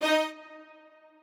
strings9_10.ogg